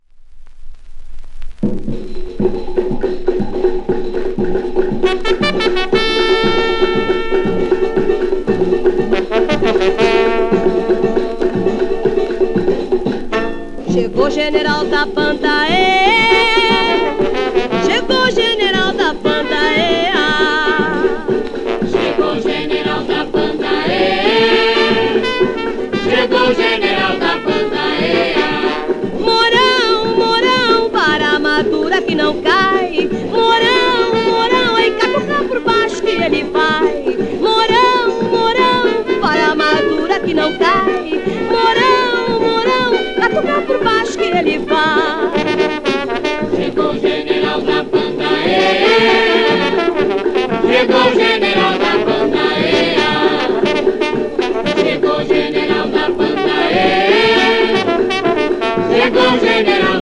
w/オーケストラ
1950年頃の録音